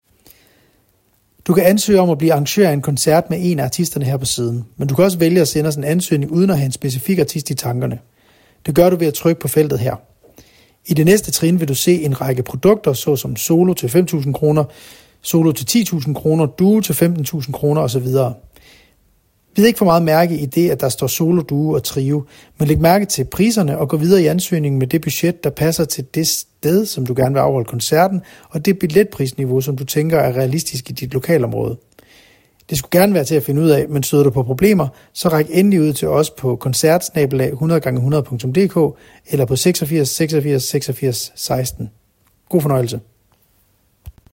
• Folk
• Jazz